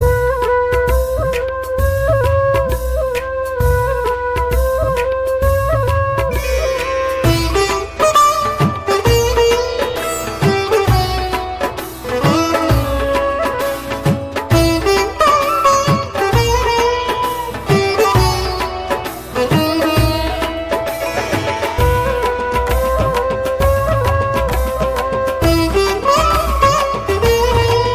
Kategori Lydeffekt